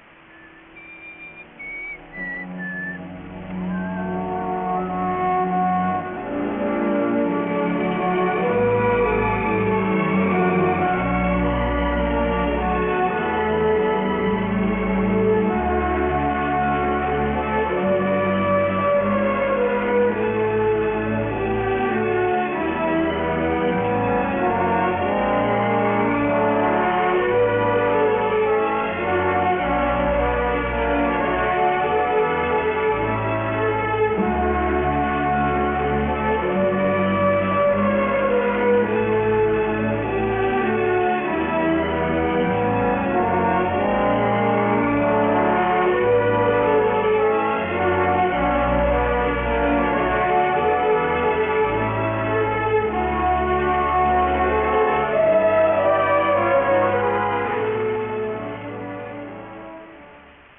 Track Music